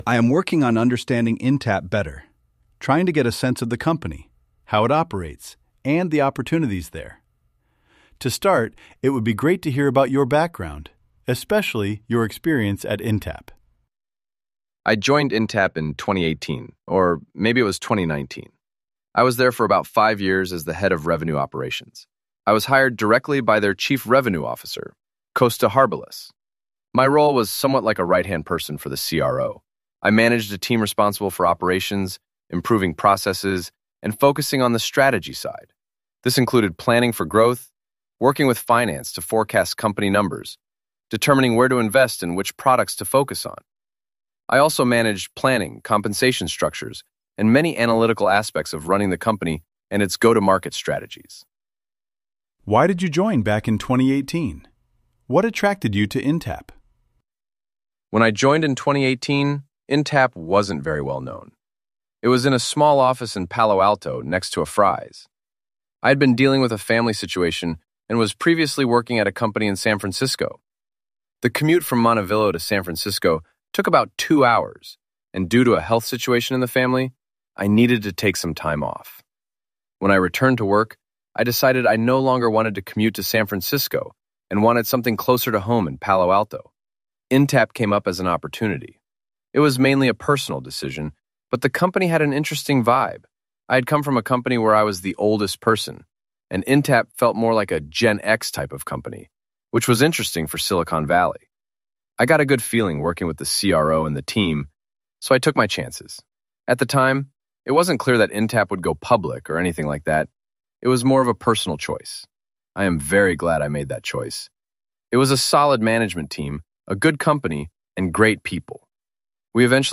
In Practise Interviews